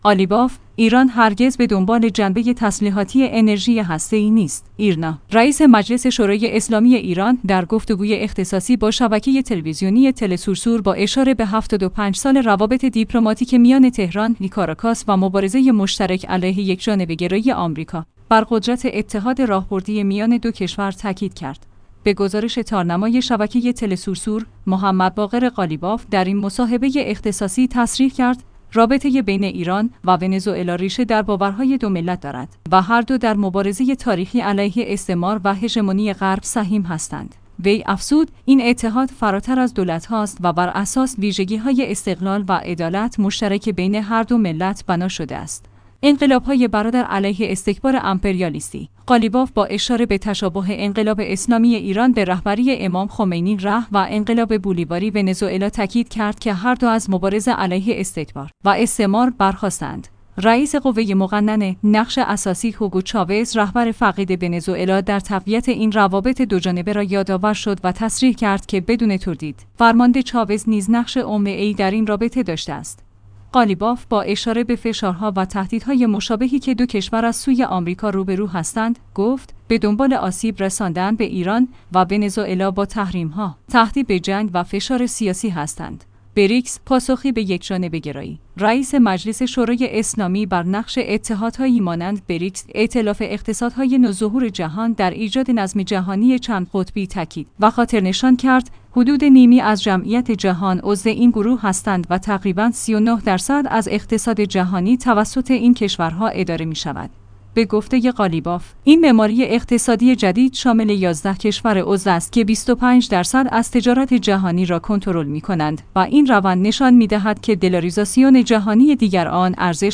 ایرنا/ رئیس مجلس شورای اسلامی ایران در گفت‌وگوی اختصاصی با شبکه تلویزیونی تله‌سور ونزوئلا با اشاره به ۷۵ سال روابط دیپلماتیک میان تهران- کاراکاس و مبارزه مشترک علیه یکجانبه‌گرایی آمریکا، بر قدرت اتحاد راهبردی میان دو کشور تاکید کرد.